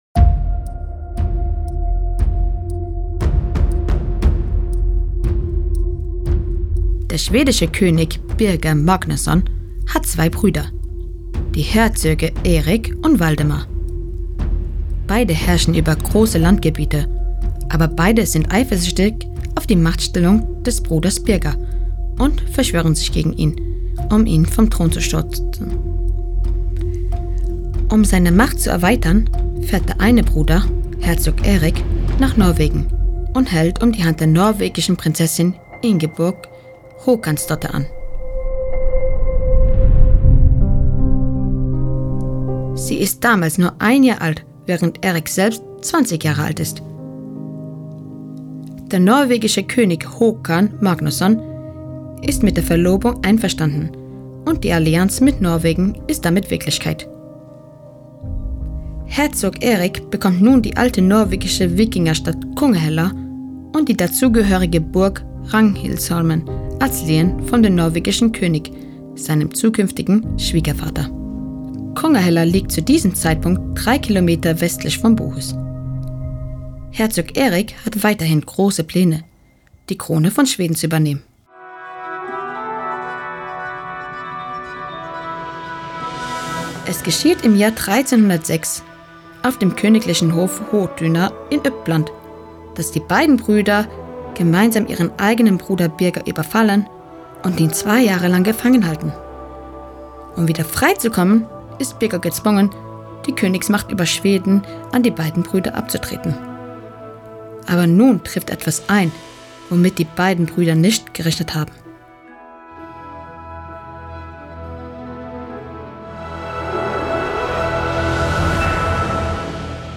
Erzählerstimme
Mit diesem Audioguide tauchst du ein in die dramatische Geschichte der Festung Bohus – von mittelalterlichen Machtspielen und königlichen Hochzeiten bis hin zu blutigen Belagerungen, Gefängniszellen und Hexenprozessen.